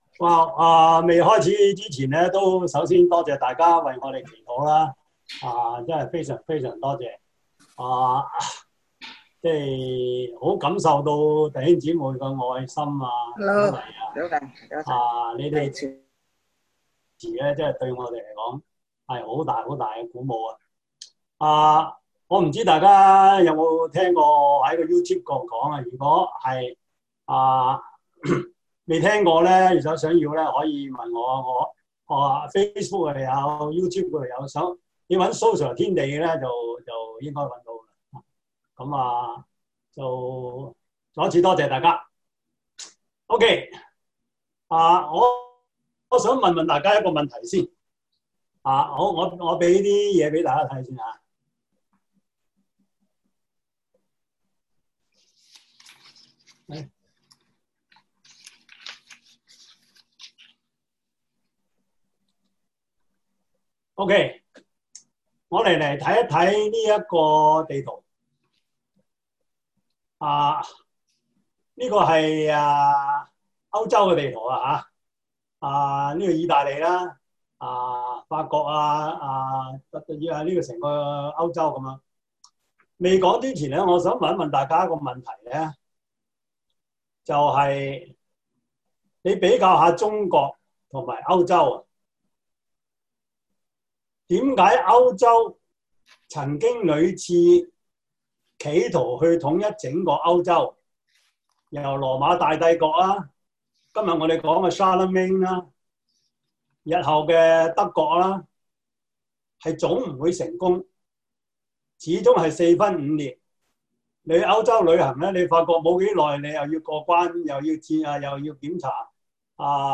教會歷史 Service Type: 中文主日學 Preacher